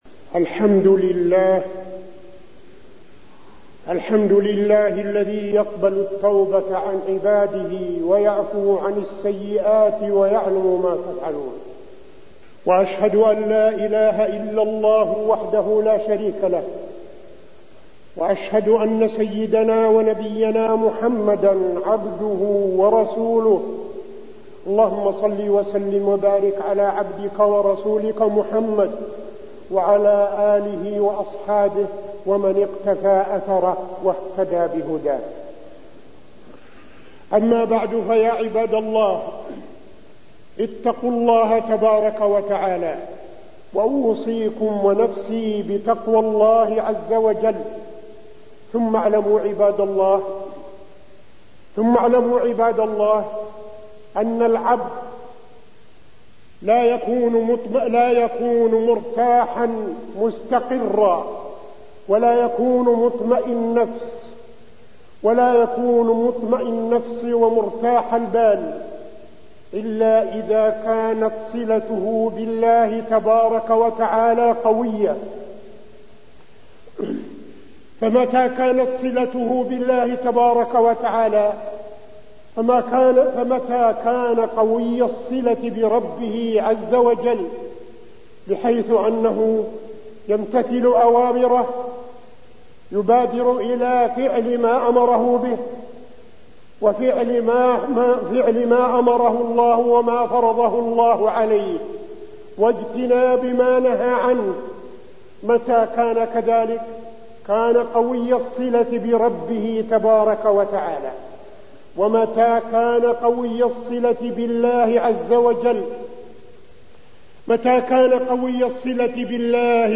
تاريخ النشر ١٧ جمادى الآخرة ١٤٠٨ هـ المكان: المسجد النبوي الشيخ: فضيلة الشيخ عبدالعزيز بن صالح فضيلة الشيخ عبدالعزيز بن صالح قوة الصلة بالله